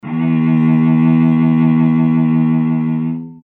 Added sound samples
cello
E2.mp3